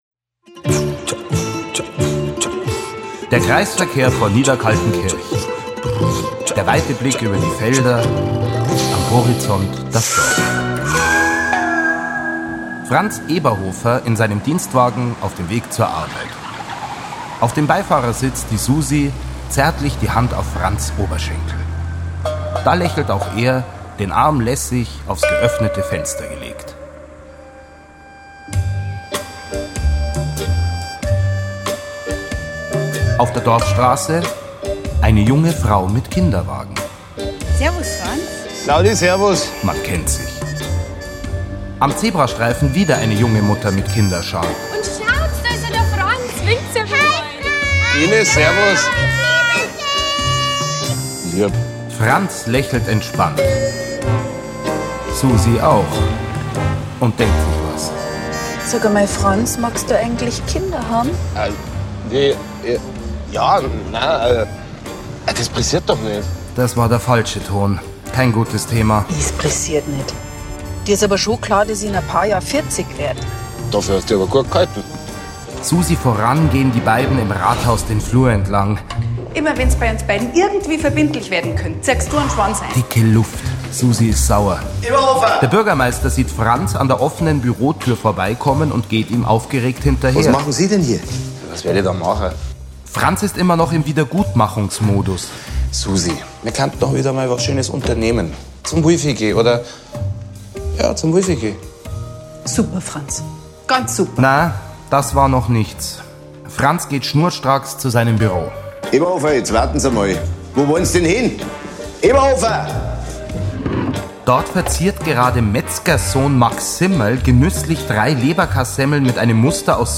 Filmhörspiel mit Sebastian Bezzel, Simon Schwarz u.v.a. (2 CDs)
Sebastian Bezzel, Simon Schwarz, Lisa Maria Potthoff (Sprecher)